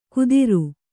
♪ kudiru